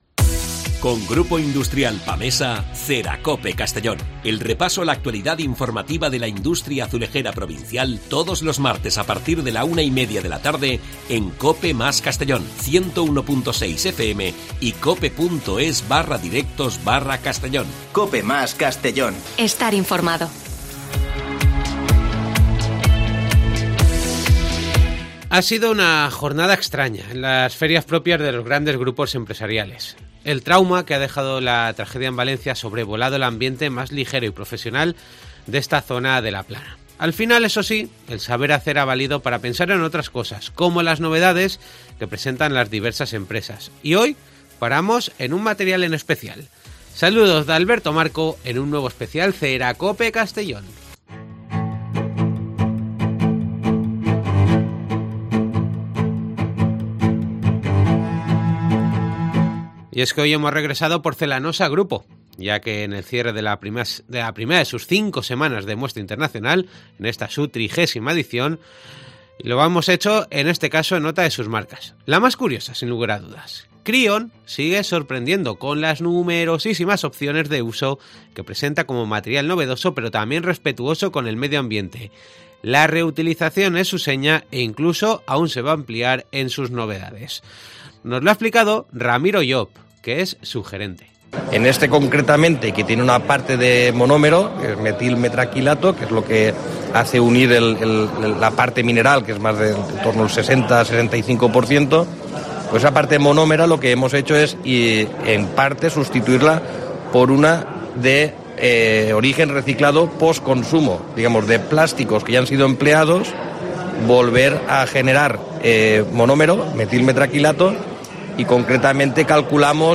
Hoy programa especial desde la Muestra Internacional de Porcelanosa, con protagonismo para Krion.